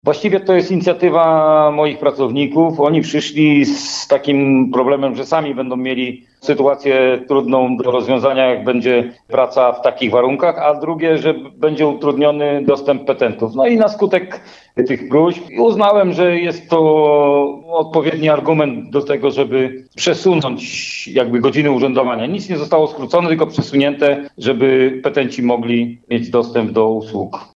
Wójt gminy Krzysztof Mrzygłód nie zgadza się z zarzutami. Jego zdaniem zmiana godzin funkcjonowania urzędu była podyktowana chęcią zapewnienia płynnej obsługi interesantów.